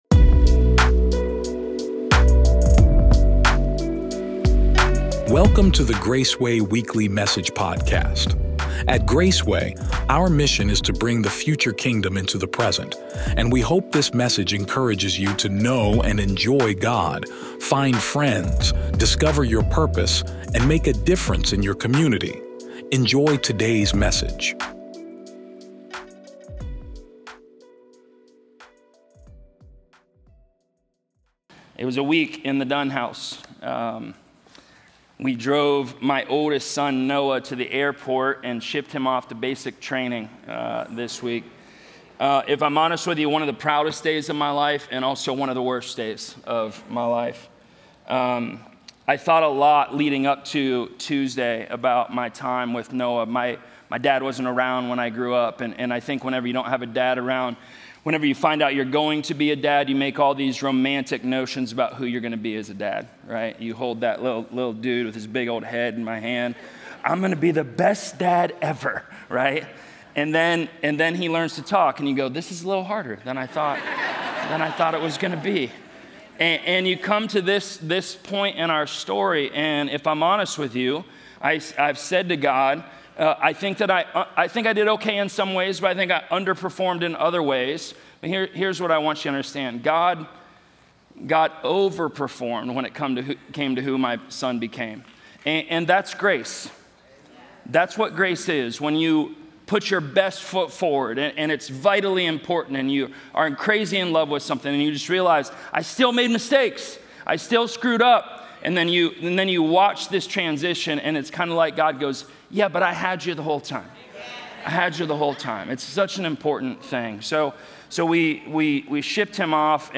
Graceway Sermon Audio Podcast